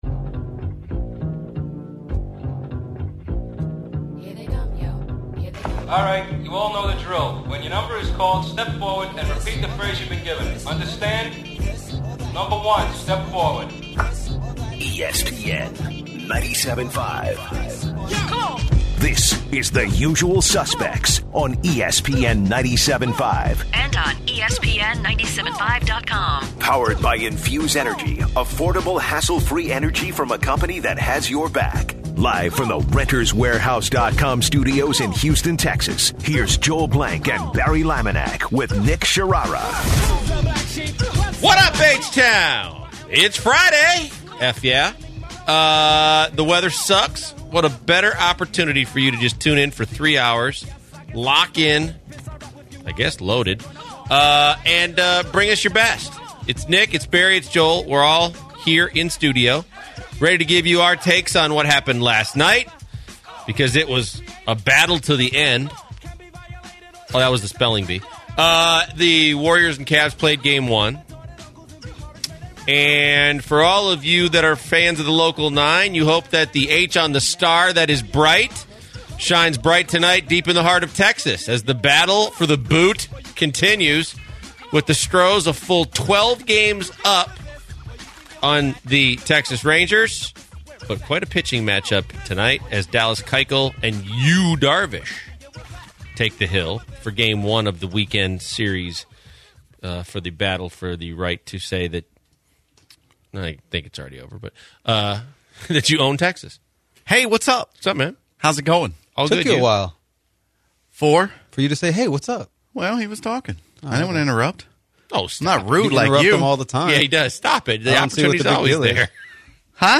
In hour one, the guys go into homeless people crazy stories and took listeners calls on homeless people.